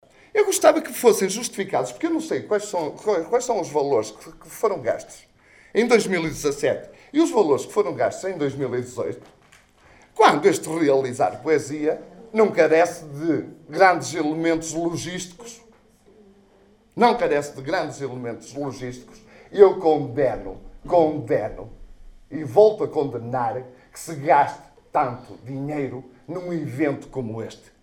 De voz firme, João Cunha disparou o juízo final.